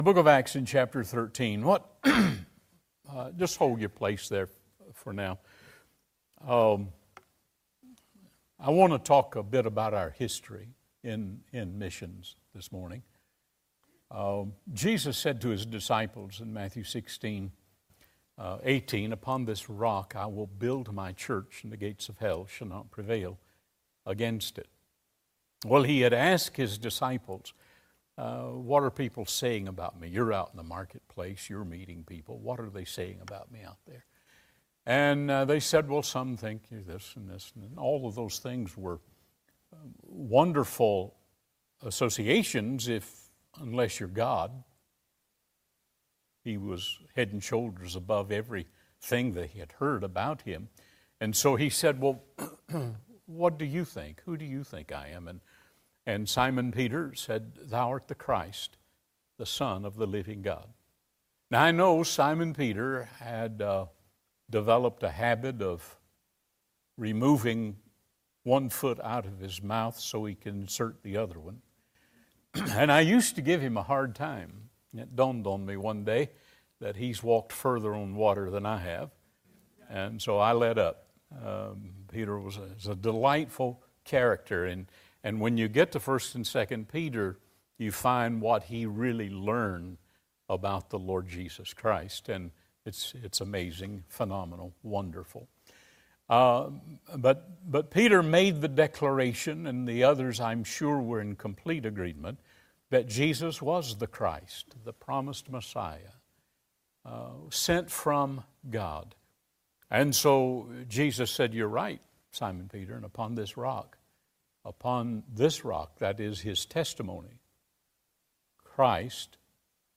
2025 Missions Conference Passage: Acts 13:1-12 Service Type: Adult Sunday School Class Topics